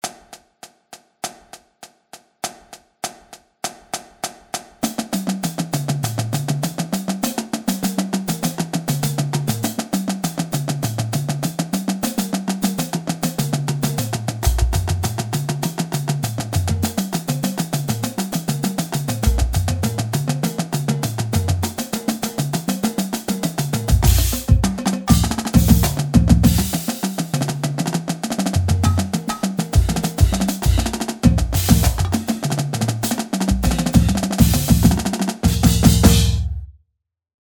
Stick Control hereta - 100.mp3